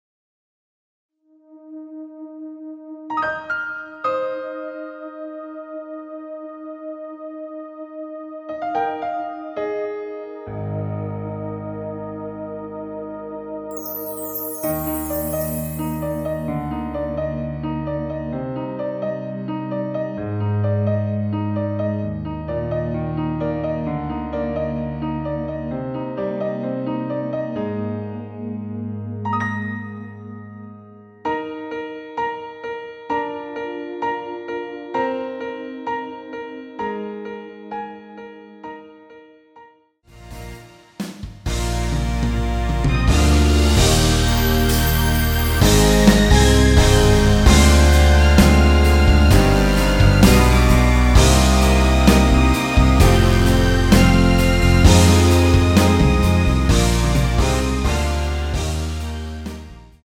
미리듣기는 저작권법상 최고 1분 까지라서
앞부분30초, 뒷부분30초씩 편집해서 올려 드리고 있습니다.
중간에 음이 끈어지고 다시 나오는 이유는